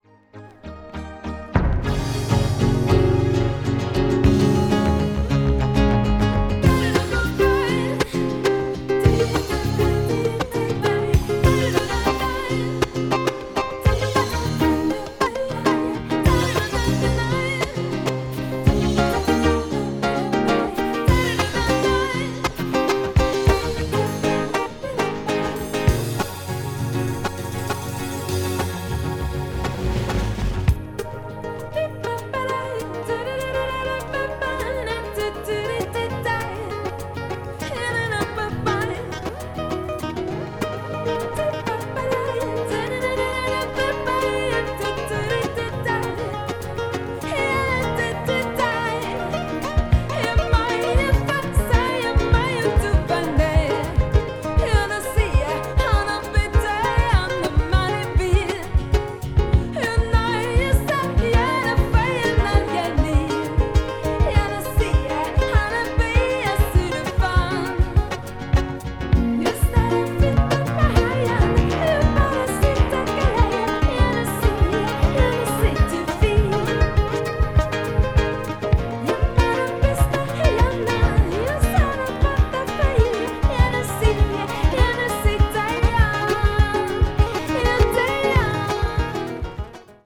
シンフォニックな展開も素晴らしいです。